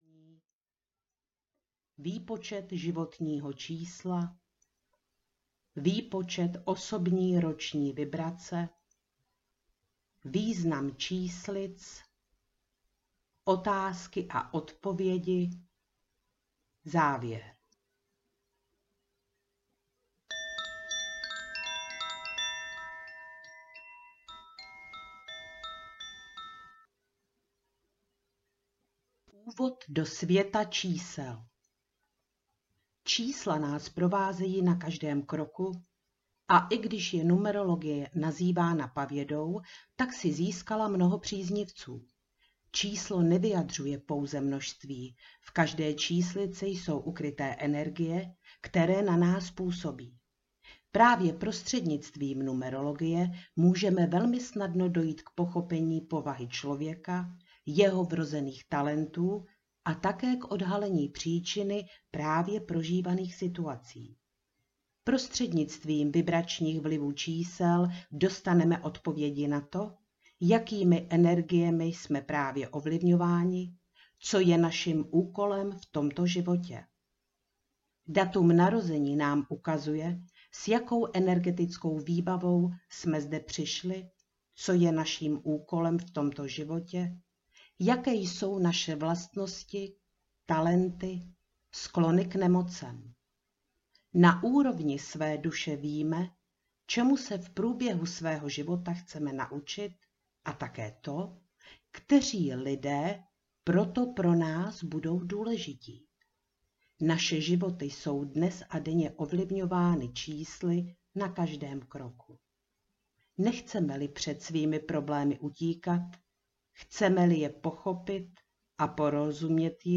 Věštba numerologickými kostkami audiokniha
Ukázka z knihy